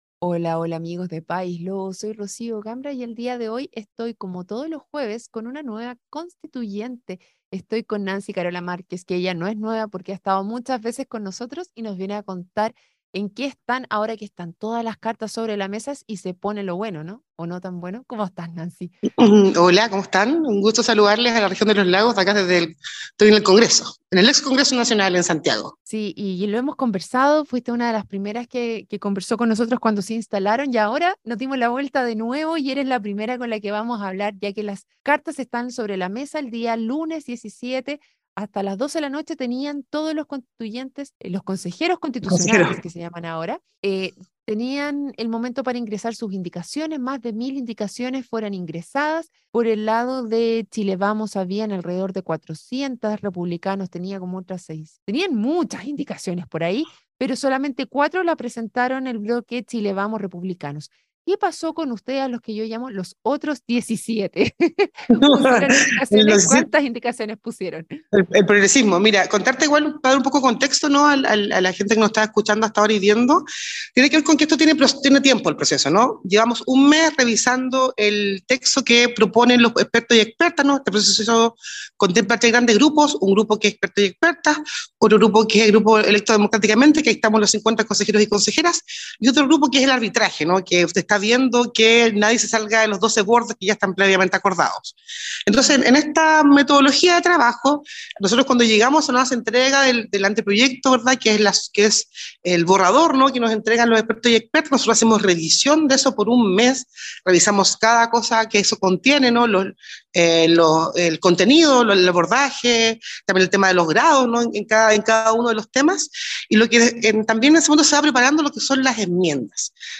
En esta oportunidad conversó con la consejera constitucional Nancy Carola Márquez, representante de la región de Los Lagos y miembro de la comisión 4: Comisión de Derechos Económicos, Sociales, Culturales y Ambientales. En esta semana han recibido las presentaciones de las iniciativas populares de norma, el consolidado de la participación ciudadana por parte de las universidades, y tuvieron que entregar las enmiendas todas las bancadas.